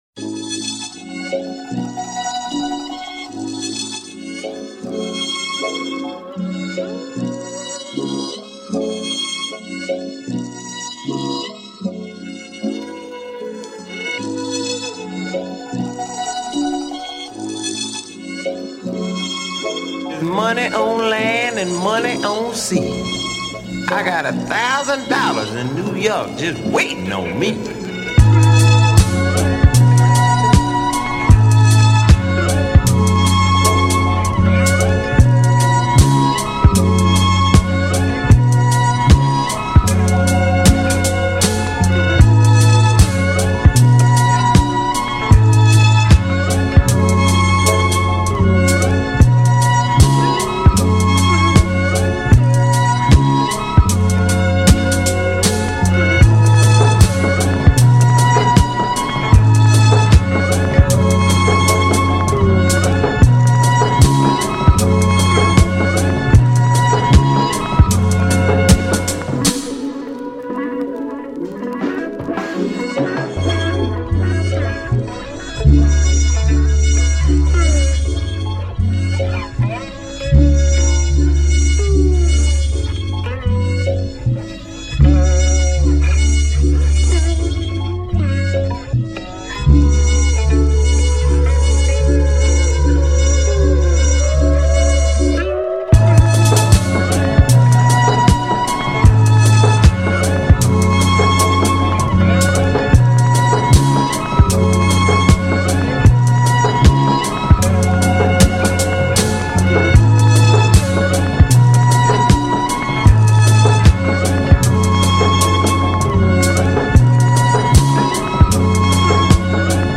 A tasty Electro Jazz podcast